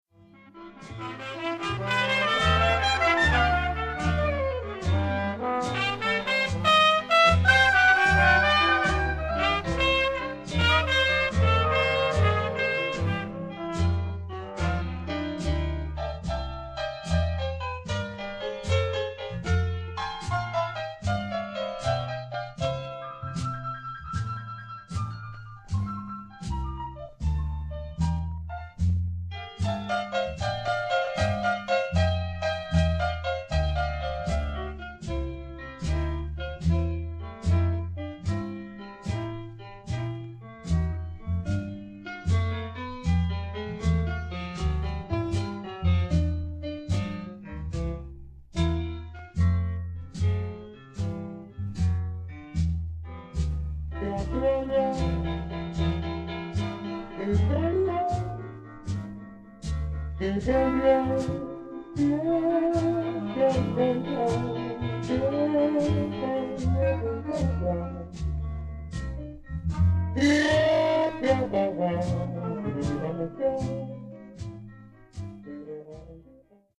trombone
First Performance